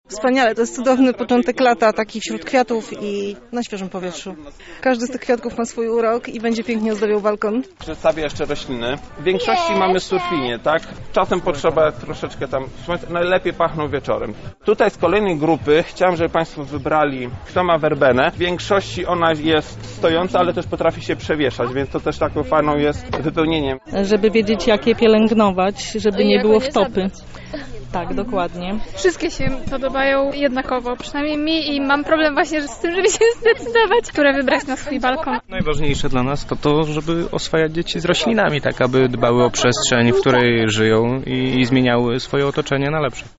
A tak o wrażeniach z warsztatów mówią uczestnicy.